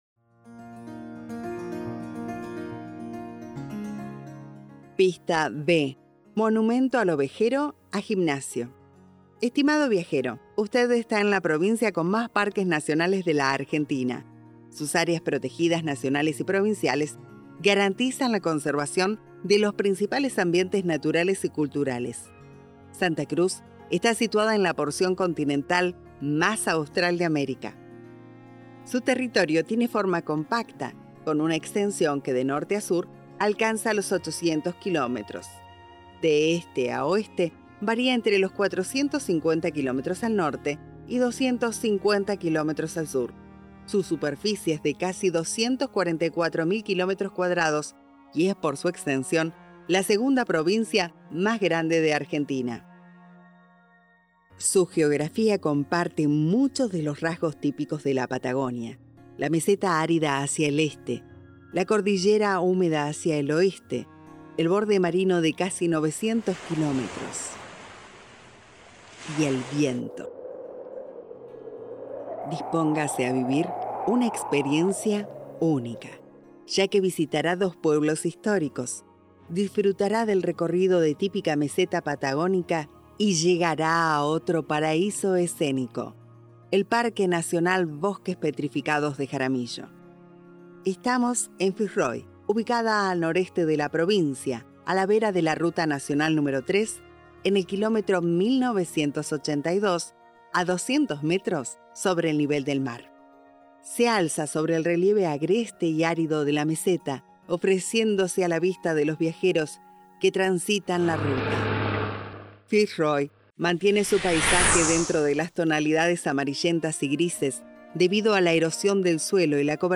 Prepárese para descubrir –de la mano de nuestra narradora– sitios que han sido testigos de grandes sucesos, fósiles de arbóreas y de la mega fauna que habitó este lugar millones de años atrás.
Para hacer más amena la propuesta, hemos incluido también producciones musicales de nuestros artistas que ilustran el paisaje y le dan color a este viaje al interior de SANTA CRUZ SONORA: un recurso de comunicación inclusivo que genera la oportunidad de disfrutar del viaje a personas con baja visión.
Bienvenidos a las audioguías vehicular del proyecto "Santa Cruz sonora", que propone la Secretaría de Estado de Turismo de la Provincia, para acompañar a los viajeros en sus travesías por Santa Cruz.